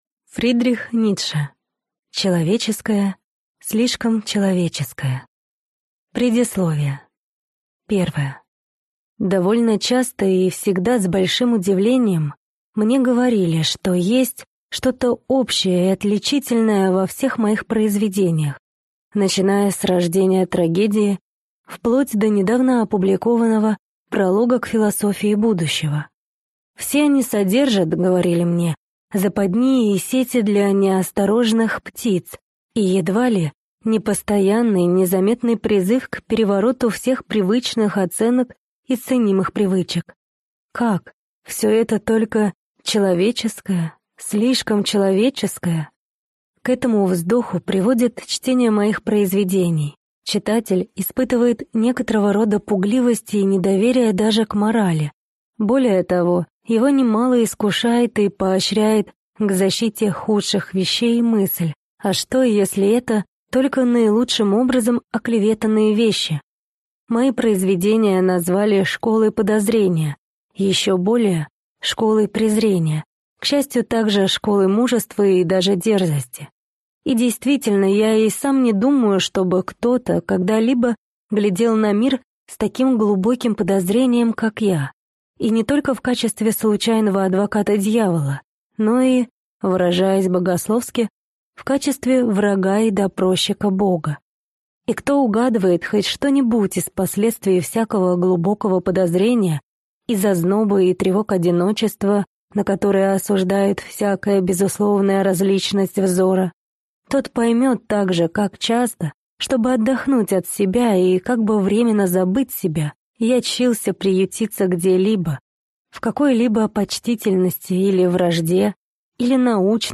Аудиокнига Человеческое, слишком человеческое | Библиотека аудиокниг